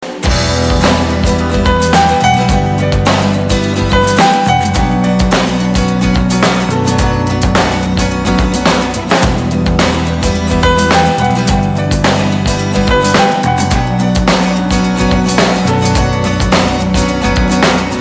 спокойные
без слов
инструментальные
русский рок
Инструментальная версия рок-рингтона